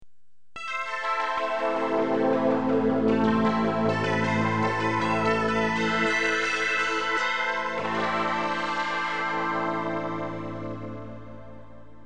synthex_bandpass.mp3